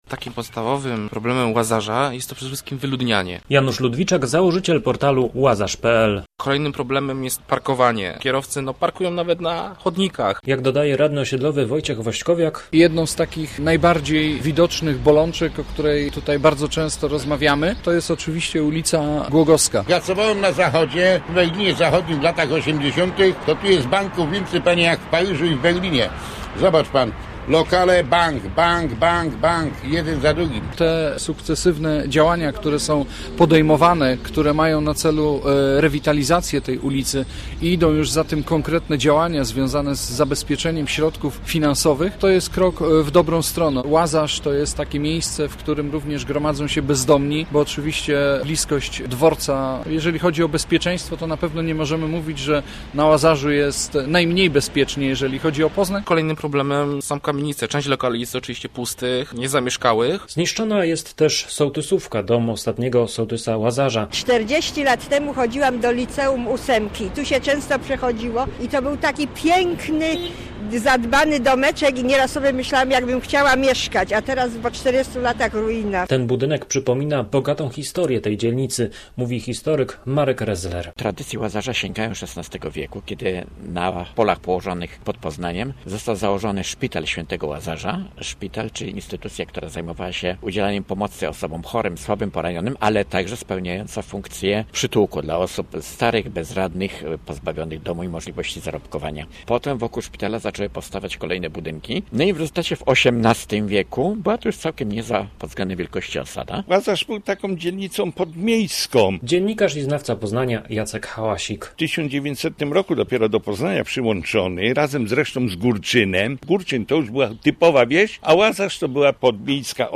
Kolorowo, głośno i radośnie - tak było w piątek wieczorem na ulicach Łazarza.